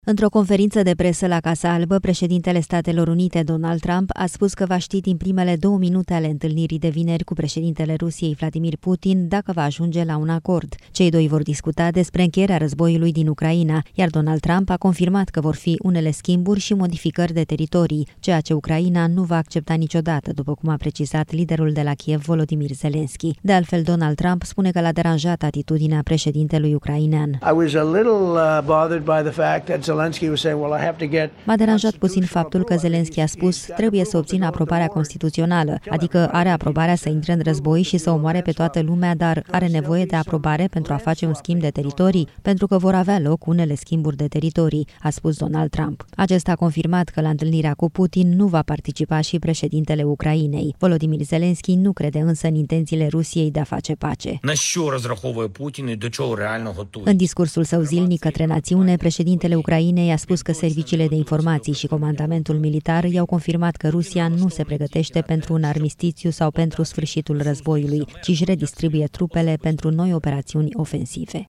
Într-o conferință de presă la Casa Albă, președintele Statelor Unite, Donald Trump a spus că va ști din primele două minute ale întâlnirii de vineri cu președintele Rusiei, Vladimir Putin dacă va ajunge la un acord.